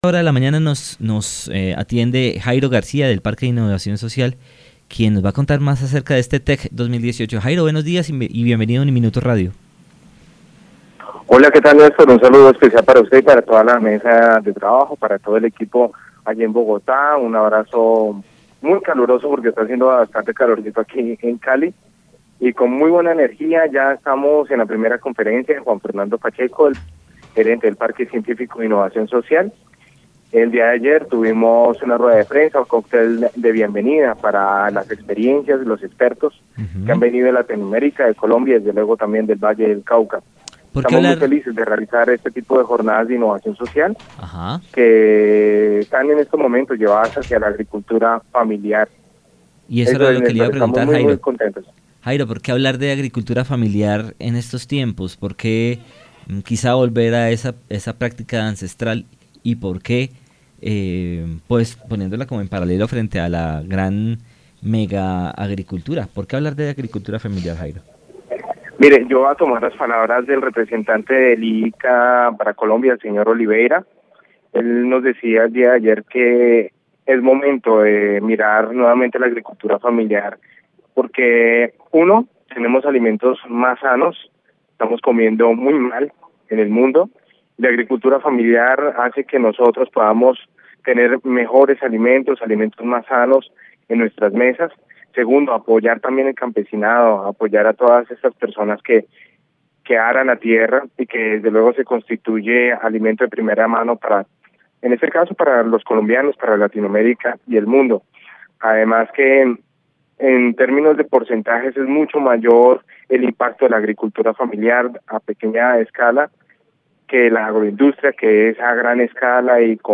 trabajador del Parque de Innovación Social en dialogo con Aquí y Ahora comento todo los temas a desarrollar en el Valle del Cauca.